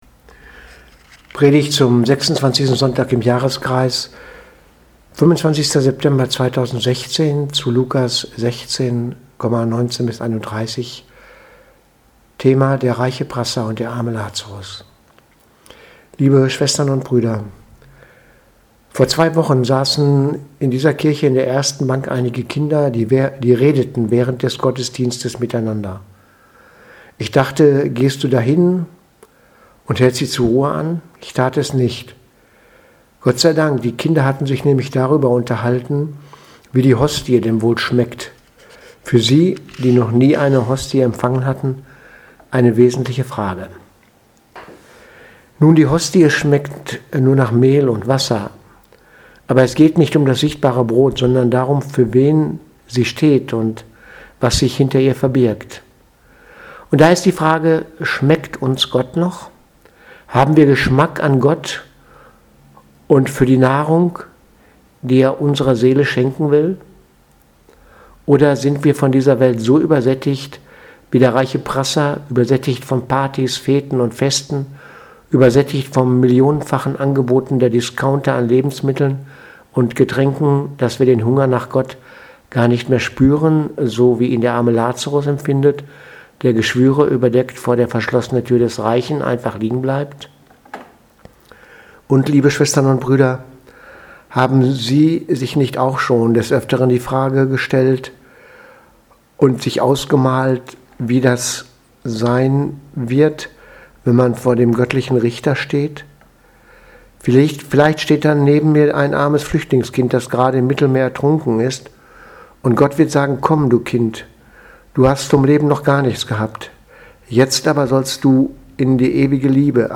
Predigt zum 26. Sonntag im Jahreskreis 2016-09-25